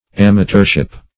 Amateurship \Am"a*teur`ship\, n.